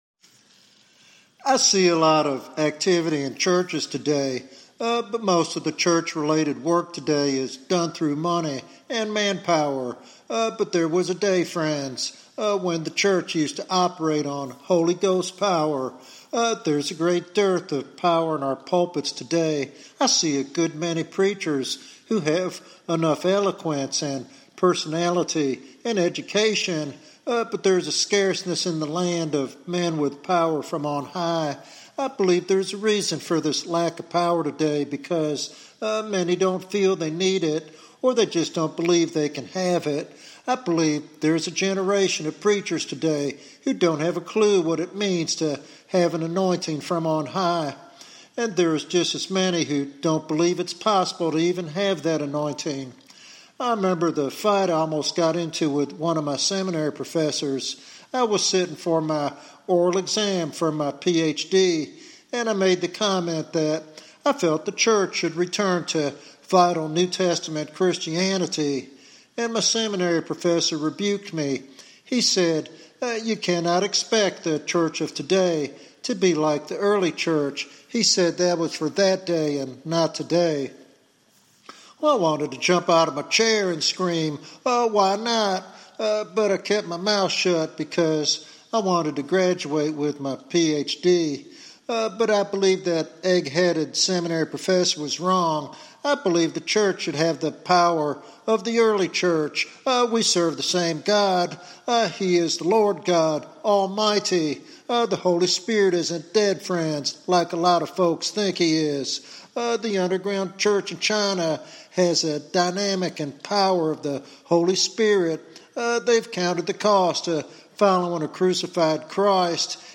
This sermon is a compelling call to believers to hunger for the Holy Spirit’s power to bring lasting spiritual transformation and influence.